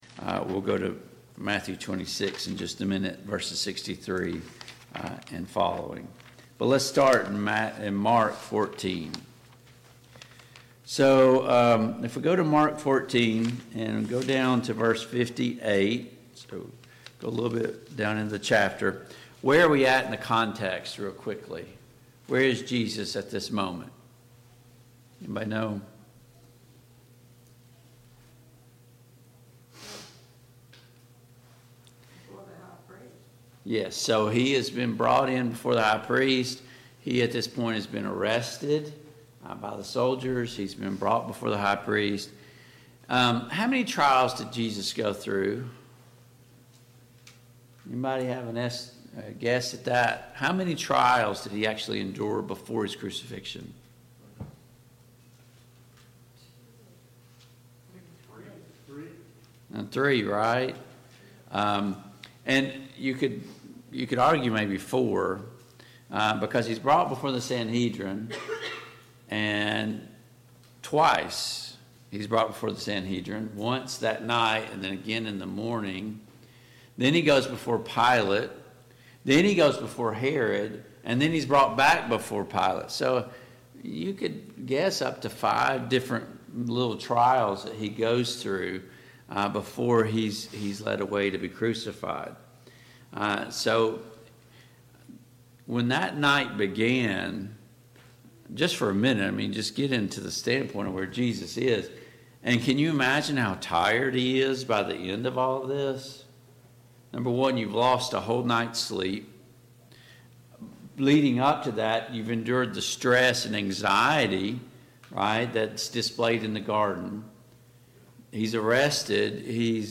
The Cast of Service Type: Sunday Morning Bible Class Topics: Pilate « 6.